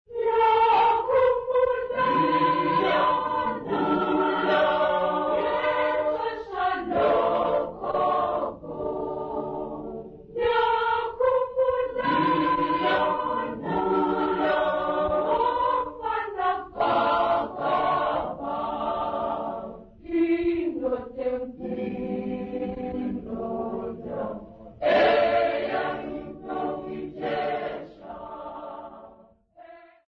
Ntsikana Memorial Church Congregation
Folk music
Sacred music
Field recordings
Africa South Africa Port Elizabeth, Eastern Cape sa
Unaccompanied church song
7.5 inch reel